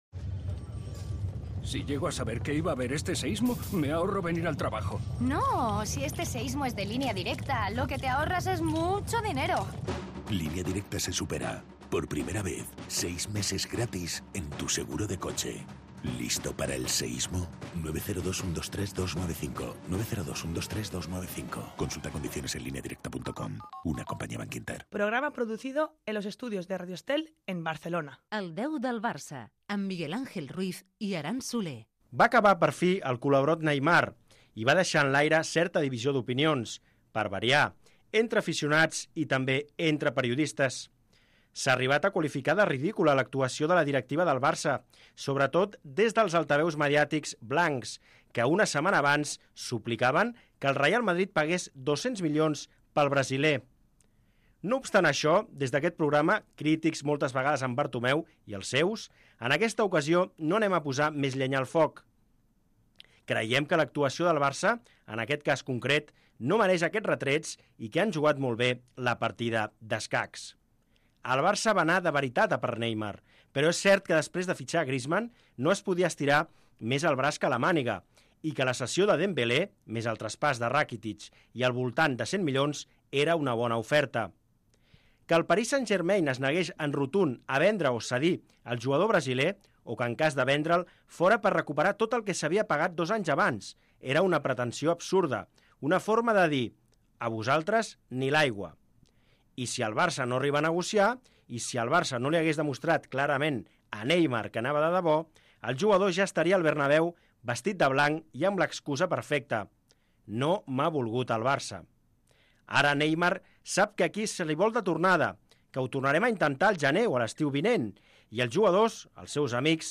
El 10 del Barça. Programa de l'actualitat del Fútbol Club Barcelona per a tots els culers del món. Amb entrevistes, tertúlia.